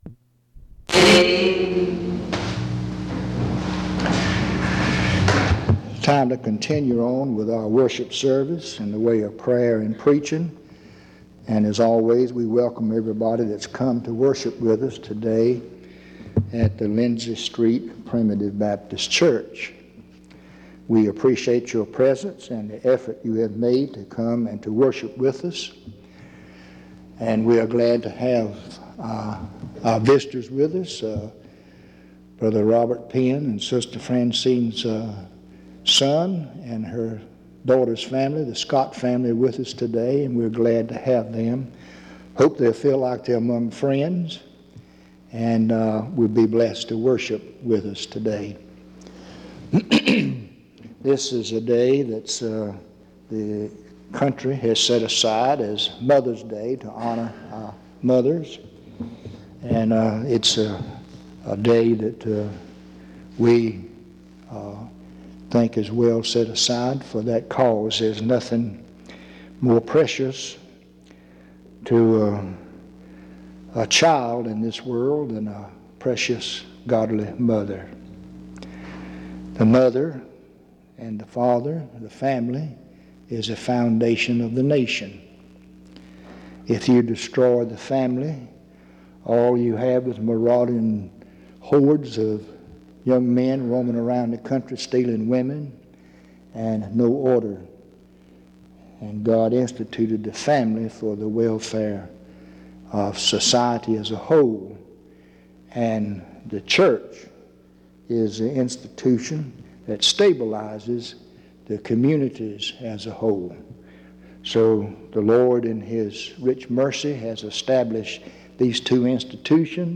In Collection: Reidsville/Lindsey Street Primitive Baptist Church audio recordings Thumbnail Titolo Data caricata Visibilità Azioni PBHLA-ACC.001_045-B-01.wav 2026-02-12 Scaricare PBHLA-ACC.001_045-A-01.wav 2026-02-12 Scaricare